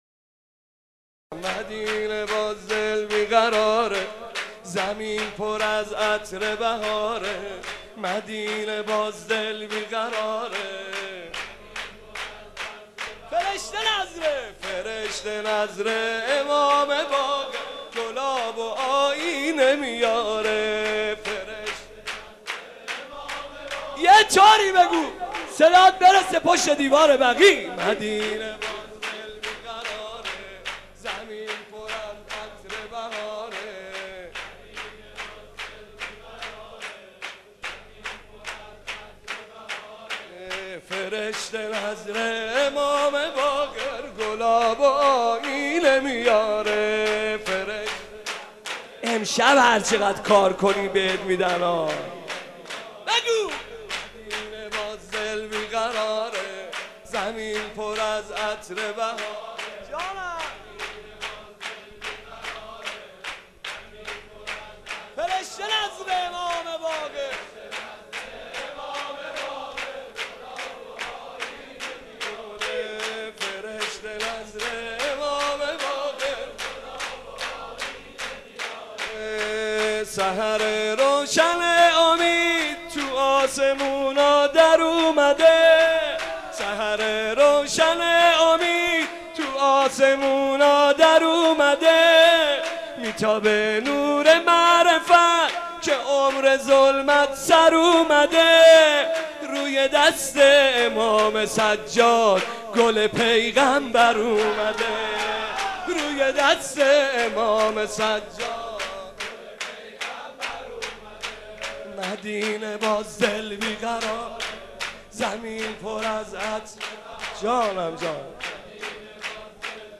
ویژه‌نامه ولادت امام باقر(ع)؛ مولودی، اشعار، احادیث و زندگی‌نامه - تسنیم
خبرگزاری تسنیم: ویژه‌نامه ولادت امام محمد باقر علیه السلام شامل زندگی‌نامه، زیارت‌نامه، تصاویر مزار متبرکه، اشعار و گلچین مداحی به‌مناسبت ولادت آن حضرت منتشر می‌شود.